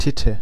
Ääntäminen
Synonyymit mammary gland (puhekieli) breast doofus ninnyhammer Ääntäminen US Tuntematon aksentti: IPA : /ˈbuːb/ Haettu sana löytyi näillä lähdekielillä: englanti Käännös Konteksti Ääninäyte Substantiivit 1.